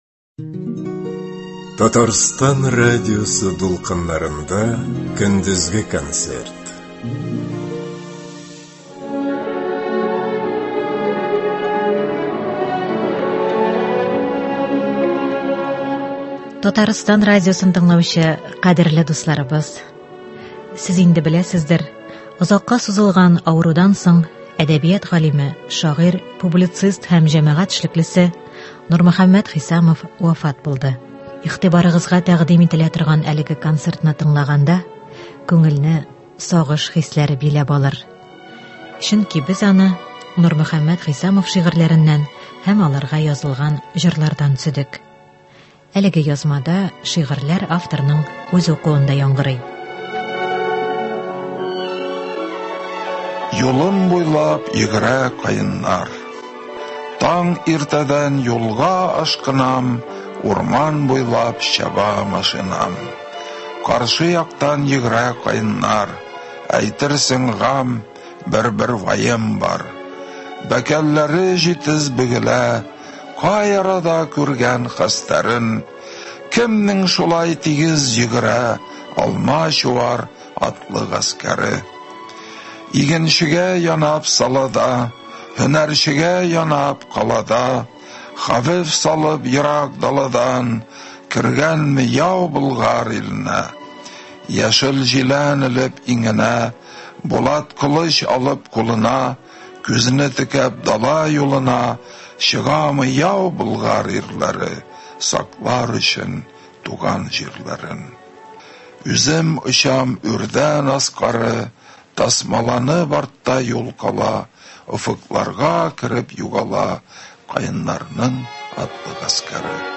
Көндезге концерт.
Эстрада концерты.